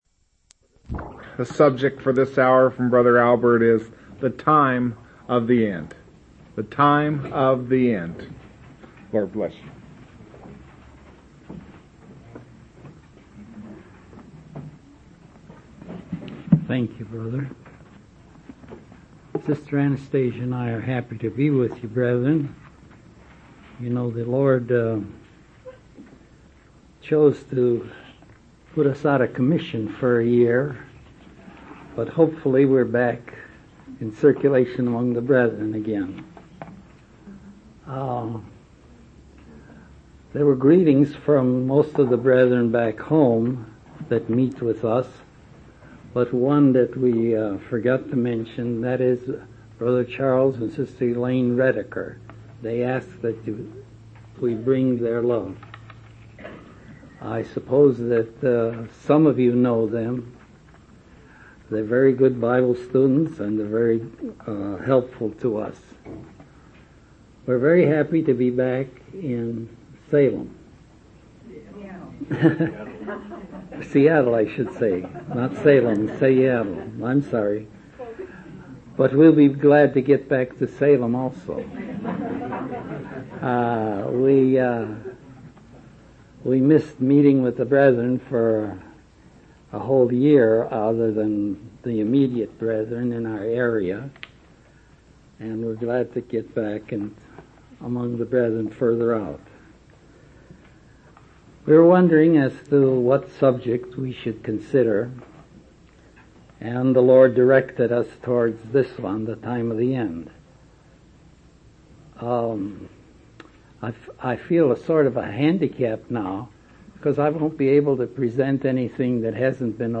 From Type: "Discourse"
Listen Seattle WA Convention 1999 Related Topics